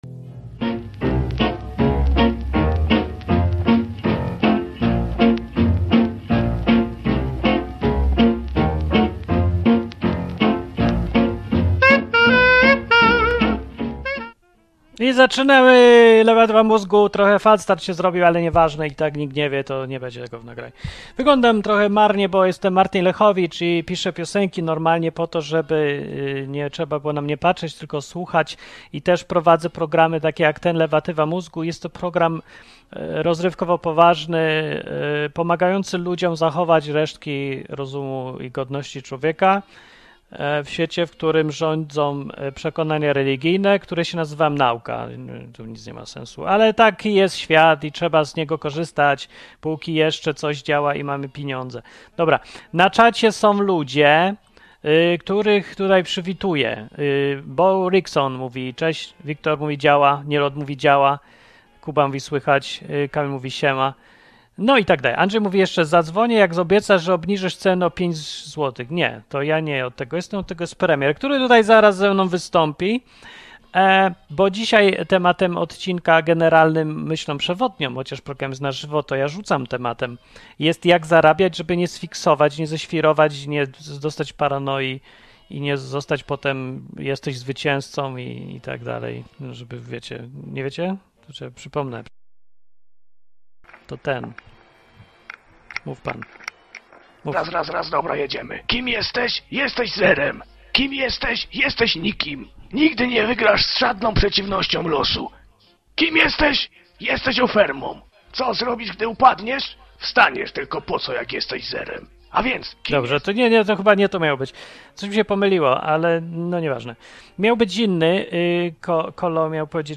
Program satyryczny, rozrywkowy i edukacyjny.